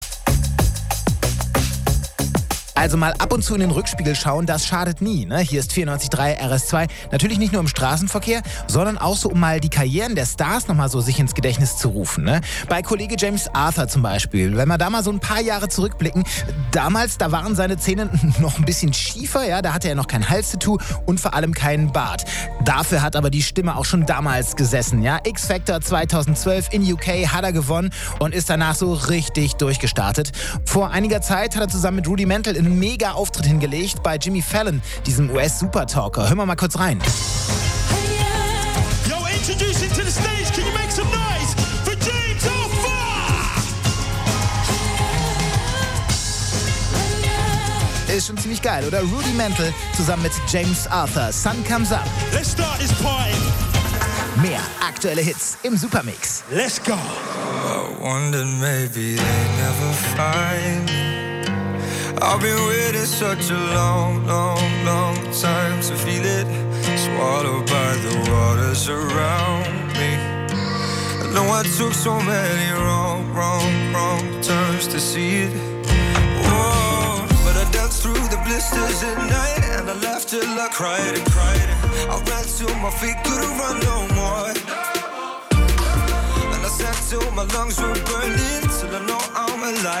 Moderation James Arthur wird mal richtig angesagt…